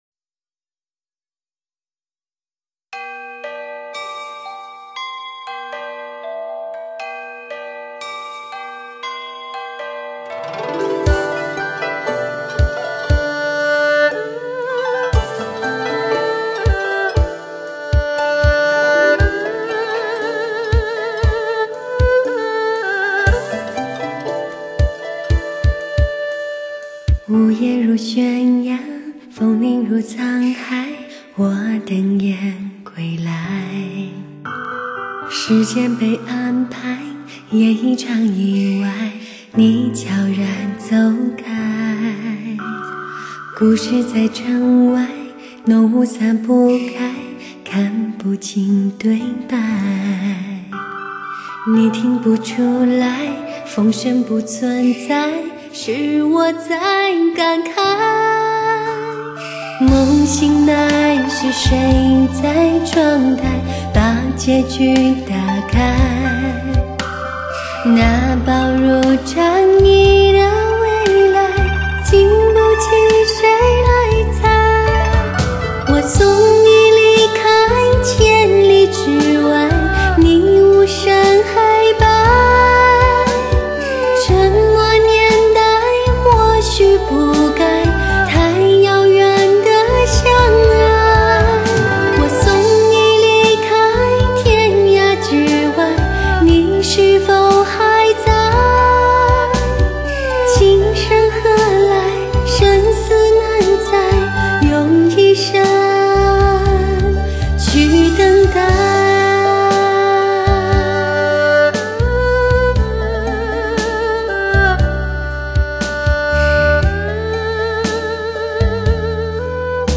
史上人声最甜美、感情最丰富的女声。